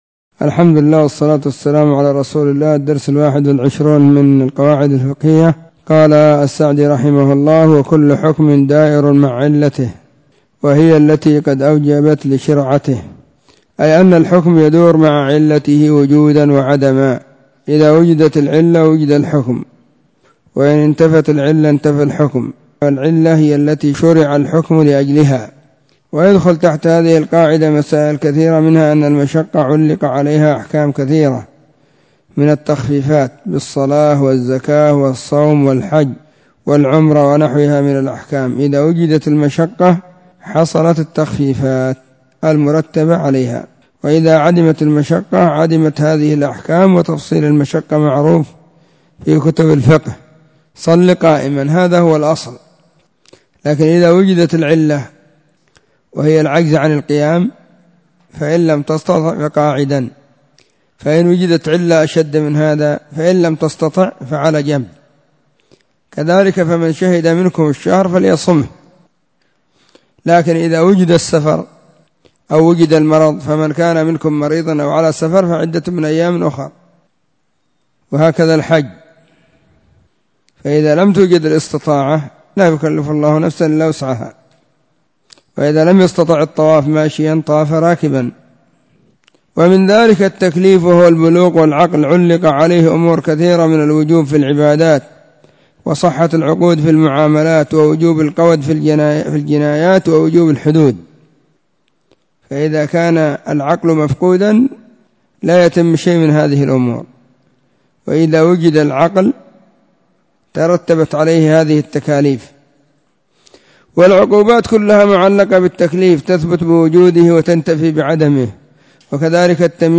منظومة القواعد الفقهية الدرس21
↔ بين مغرب – وعشاء – الدرس – الثاني
📢 مسجد الصحابة بالغيضة, المهرة، اليمن حرسها الله.